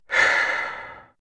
sigh3.wav